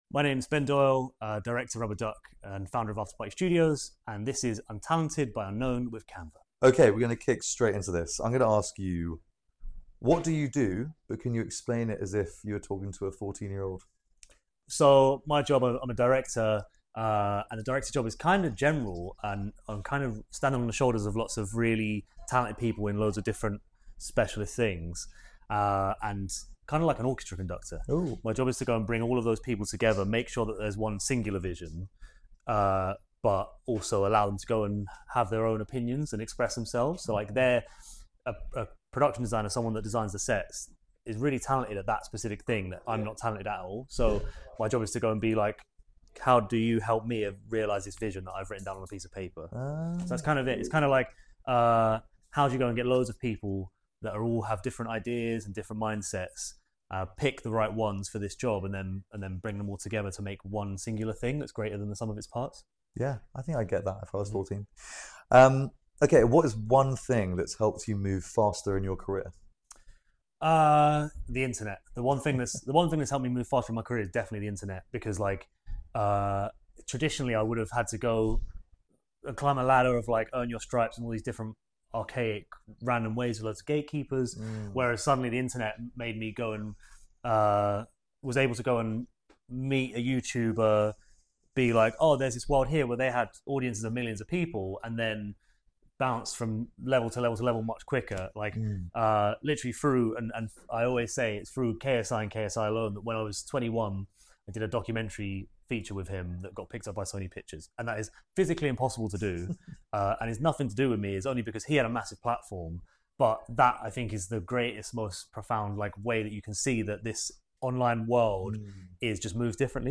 a sharp, culture-forward conversation on what it really means to create in a world that moves at the speed of the scroll.